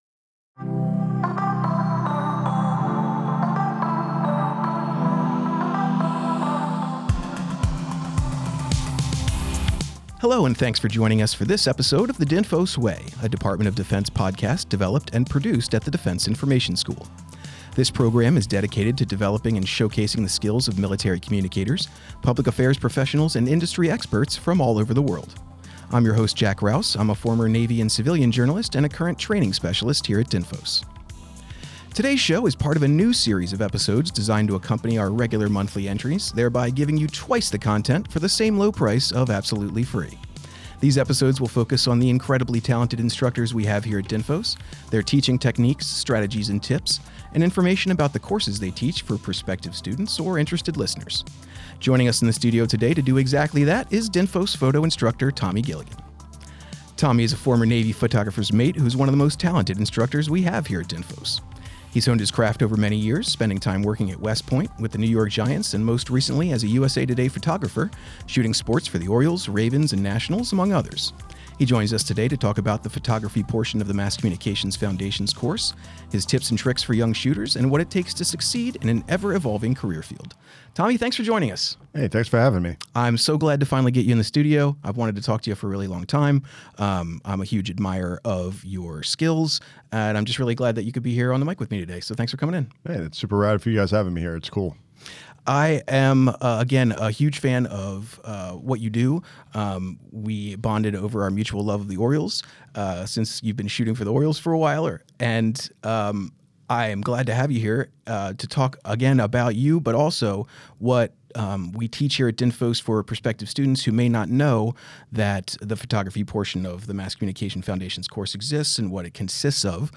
Join us for an engaging conversation packed with advice and inspiration for anyone interested in military communications and visual storytelling.